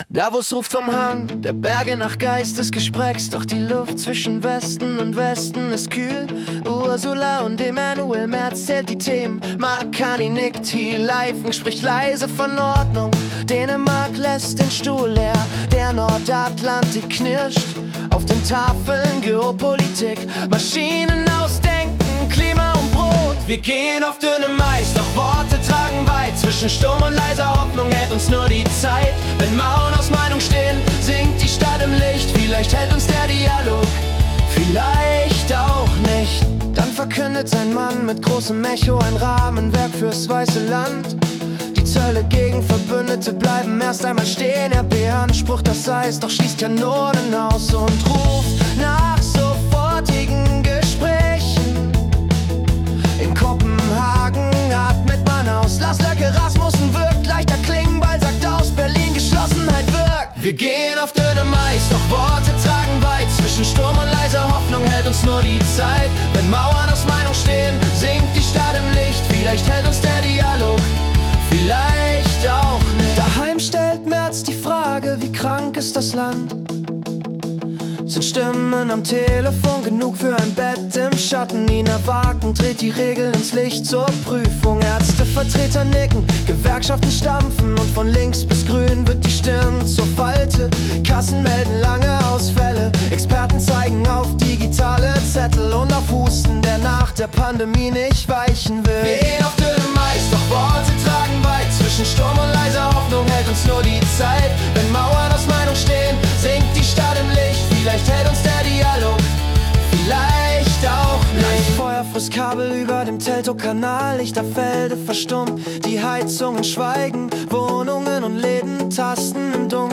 Die Nachrichten vom 22. Januar 2026 als Singer-Songwriter-Song interpretiert.